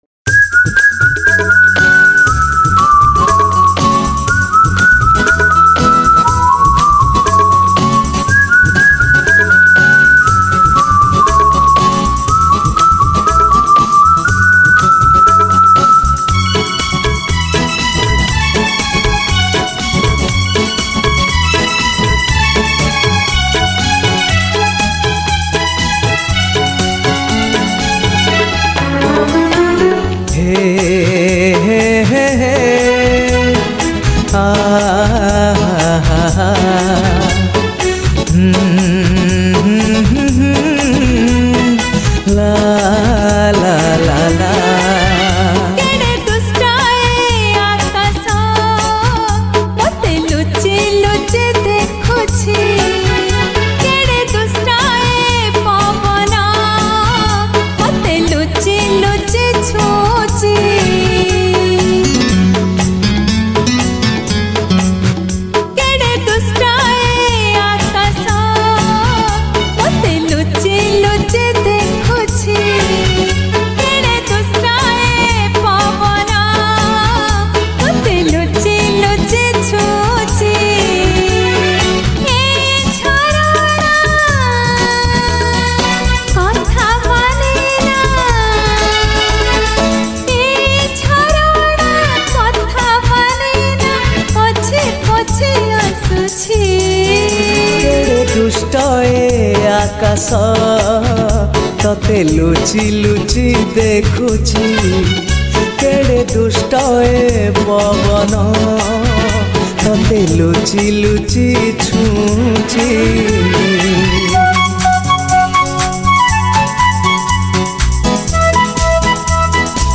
New Romantic Song